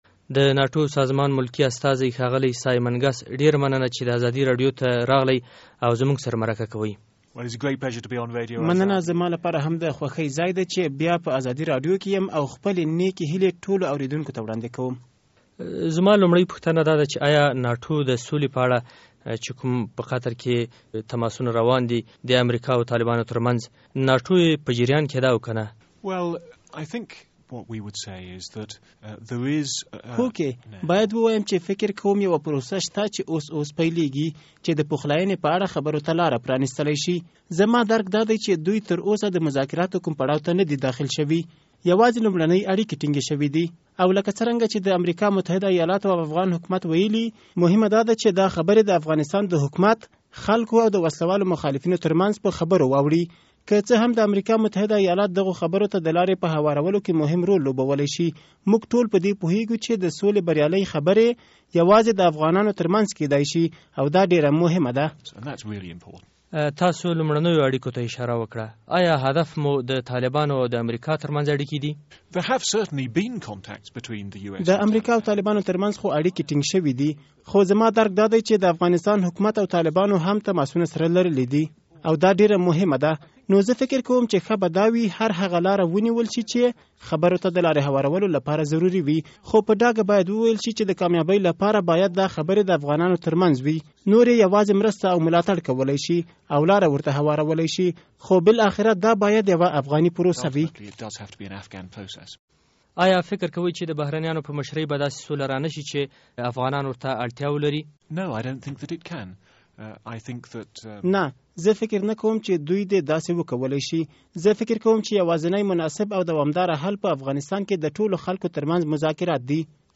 له سایمن ګس سره مرکه